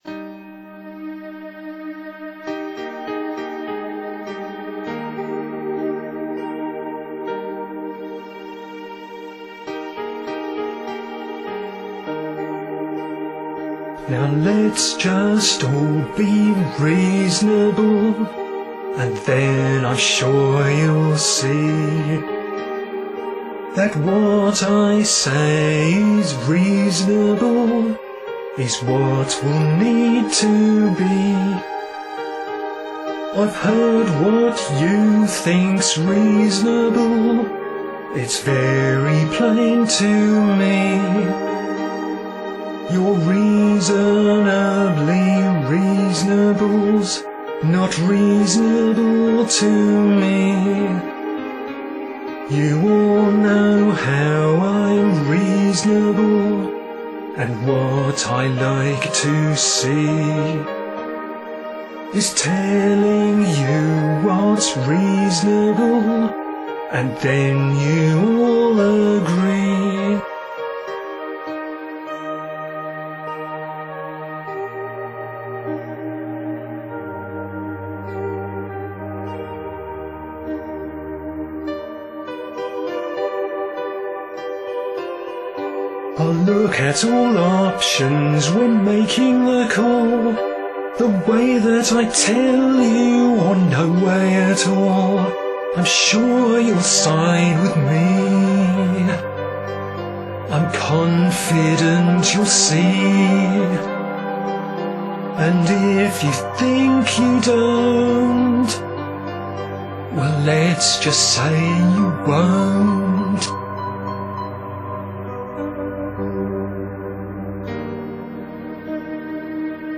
Reasonable - technical issues on this track were overcome by using a different microphone into a separate voice recorder. This was a sarcastic parody of a series of management briefings.